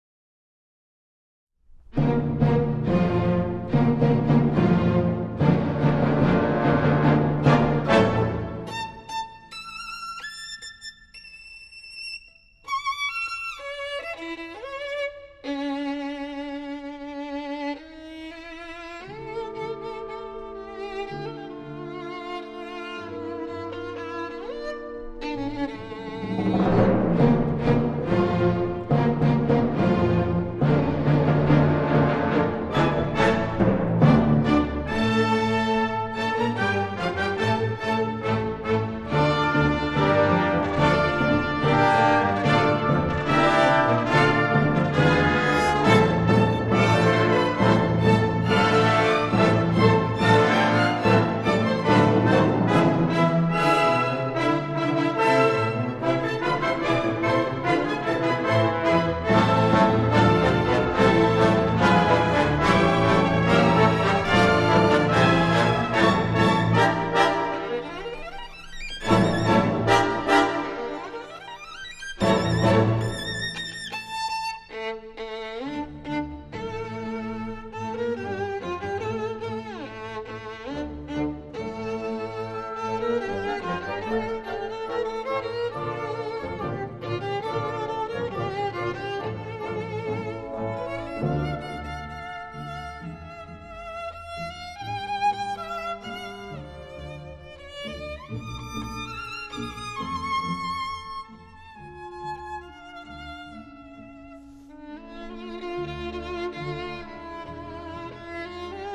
小提琴
古典音樂